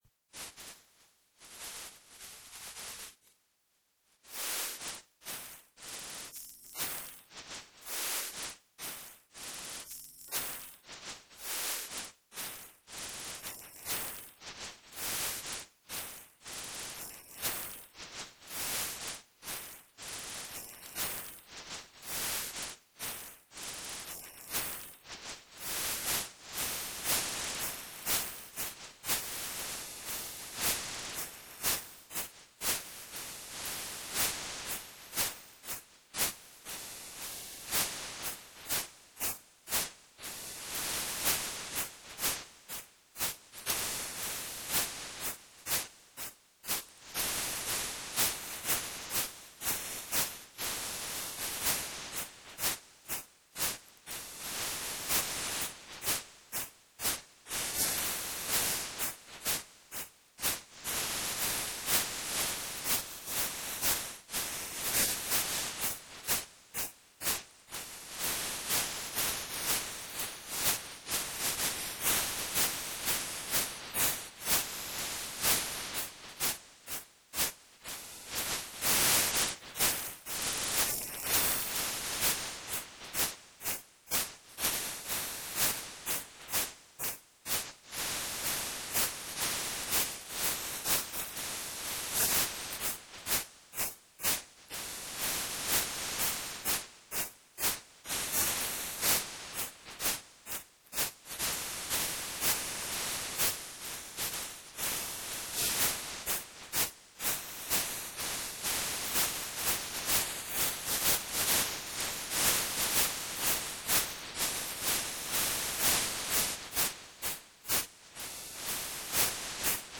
Original Sound Inverted + WMA Constant Bit Rate 64Kpbs
NulledWMA64Kbps.m4a